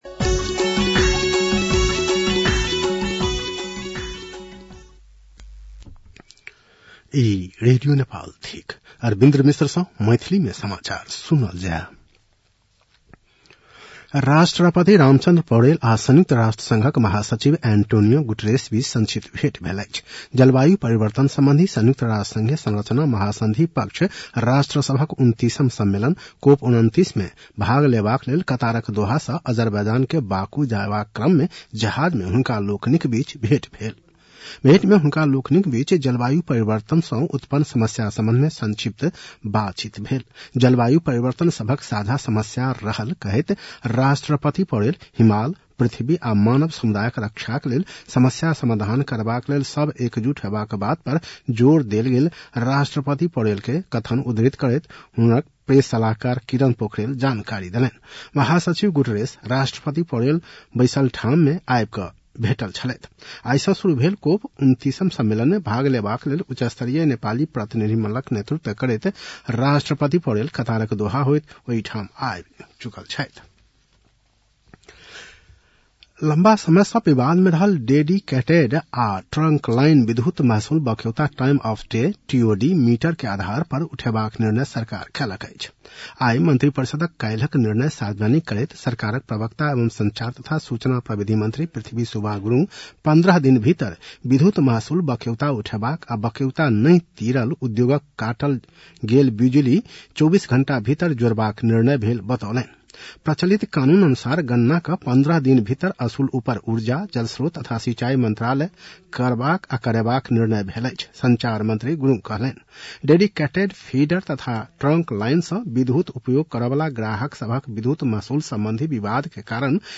मैथिली भाषामा समाचार : २७ कार्तिक , २०८१